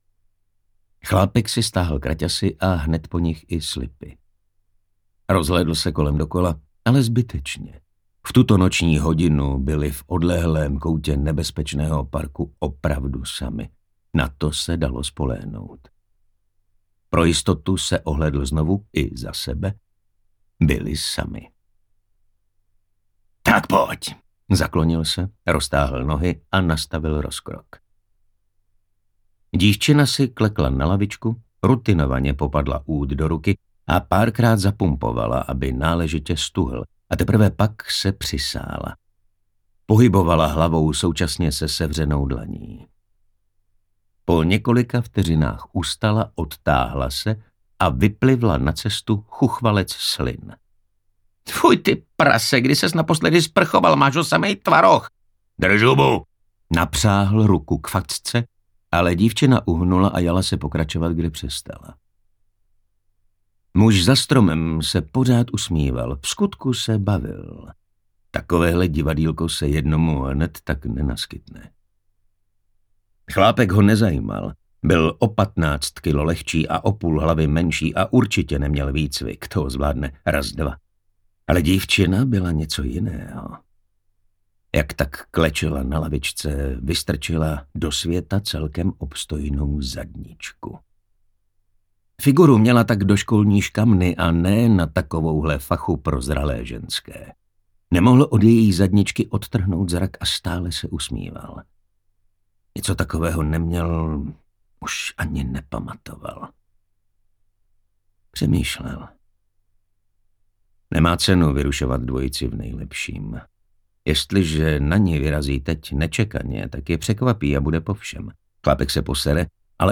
Krev není voda audiokniha
Ukázka z knihy